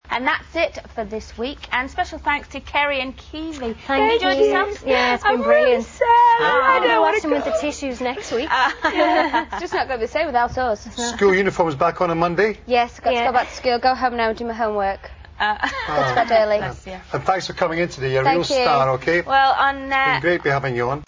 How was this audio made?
All crackling/rustling is from the microphones rubbing on clothing.